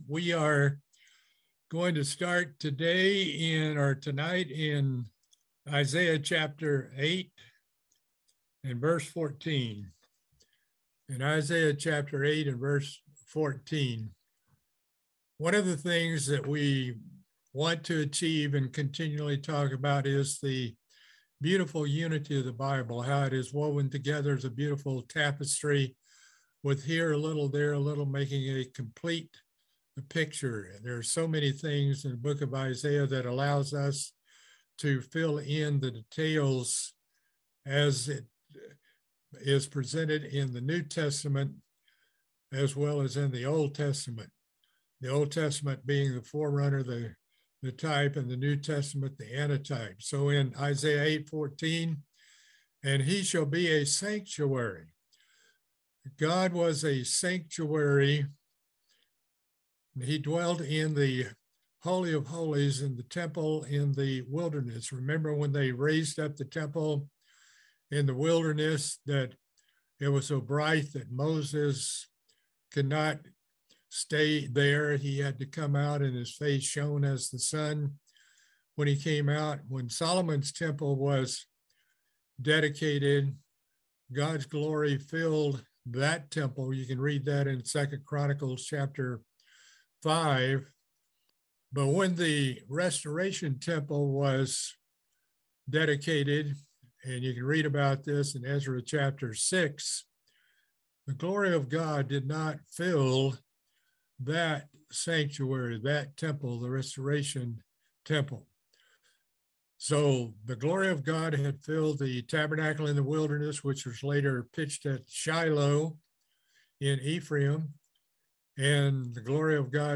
Book of Isaiah Bible Study - Part 8